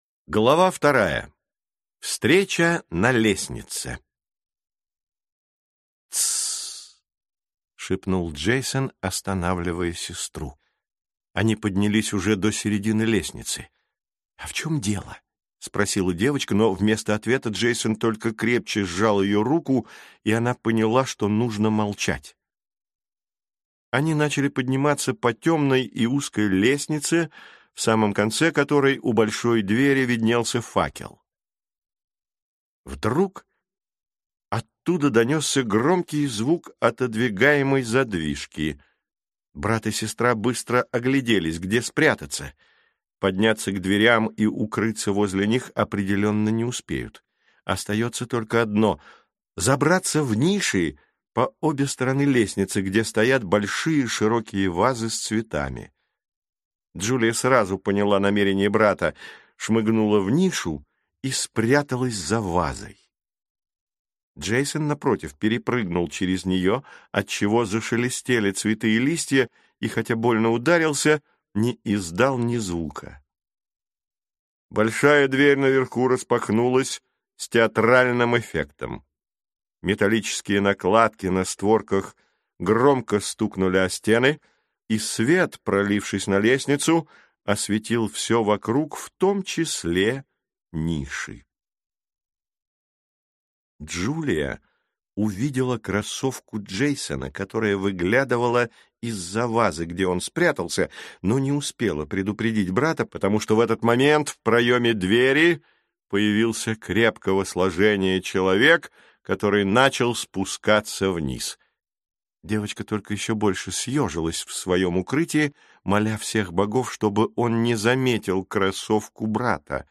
Аудиокнига Первый ключ | Библиотека аудиокниг